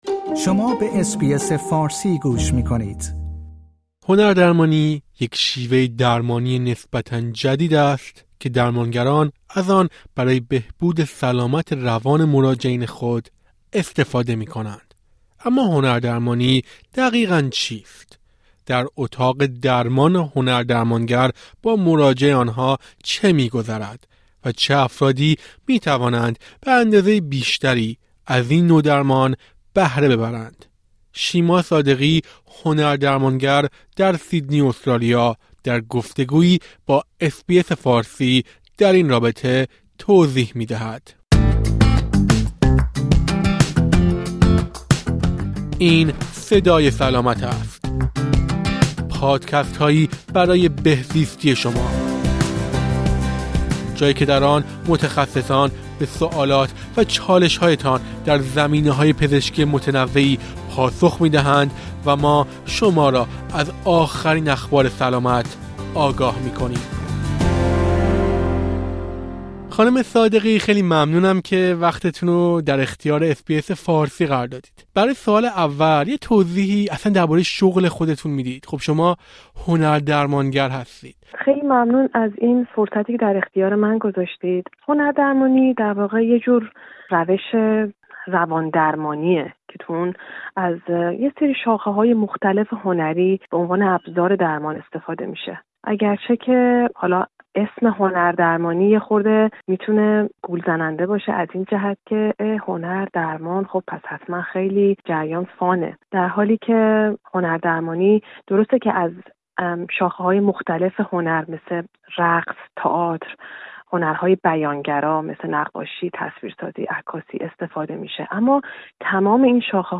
در گفت‌وگویی با اس‌بی‌اس فارسی